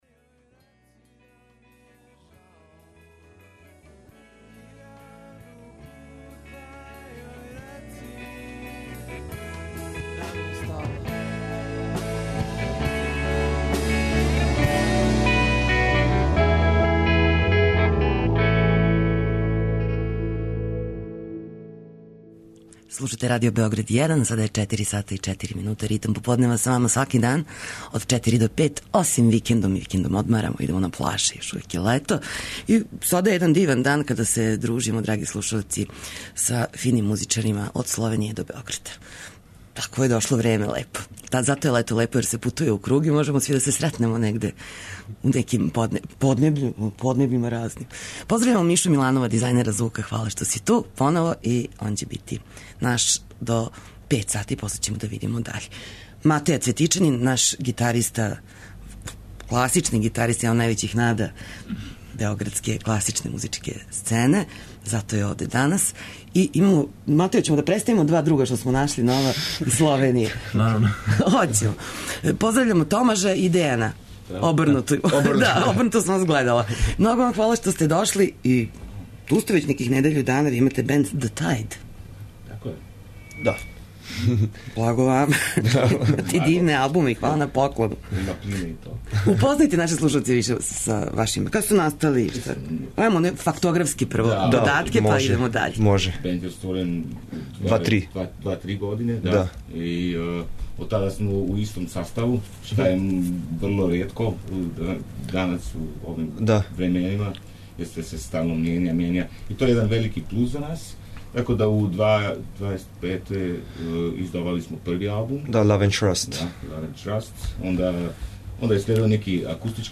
Свирамо у студију, преслушавамо његове снимке и концерте, као и музику која инспирише једног гитаристу.